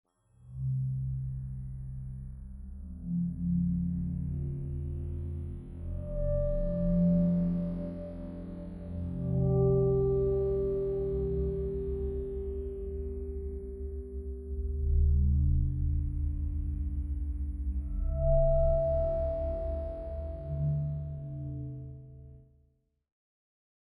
The next two audio examples demonstrate pitch shift down one octave, and the second example demos both the pitch shift with slowing playback by 10x.